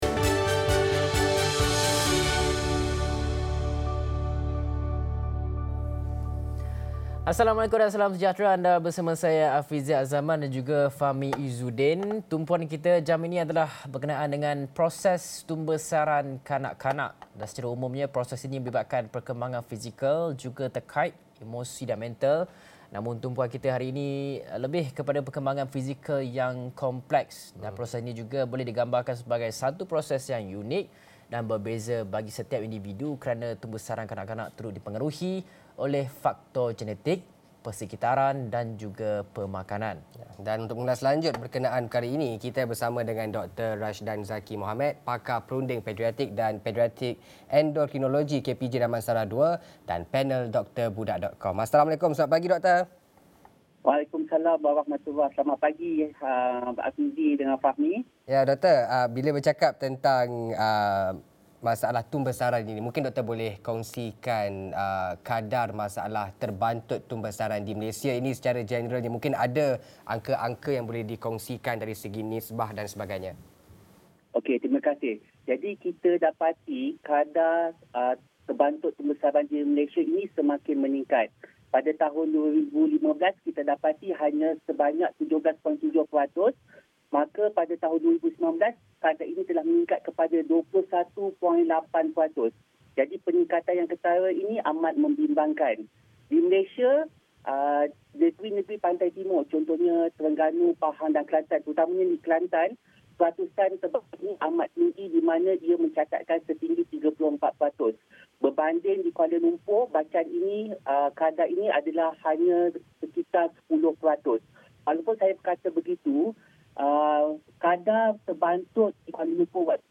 Ketahui masalah tumbesaran kalangan kanak-kanak, dan bagaimana mengesannya untuk intervensi awal. Diskusi